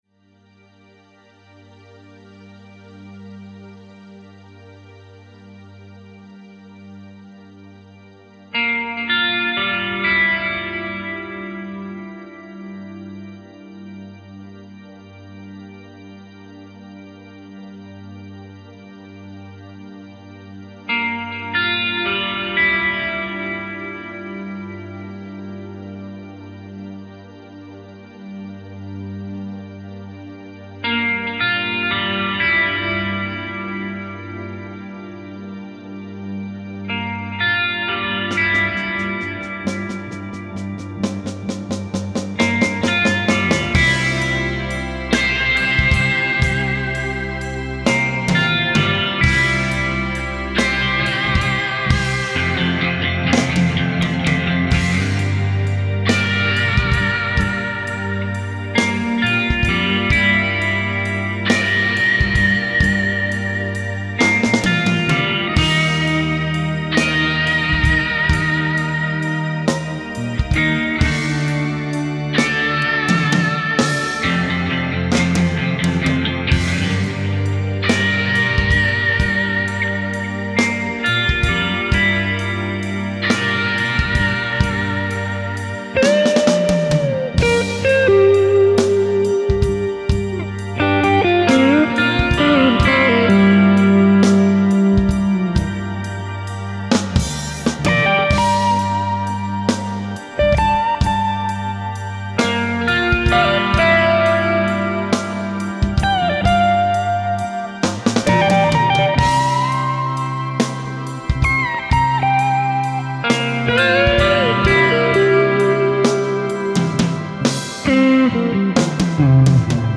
Cover of the Studio Version
• This is my AUDIO version SECOND SOLO in High Quality